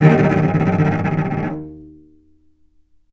vc_trm-D#2-mf.aif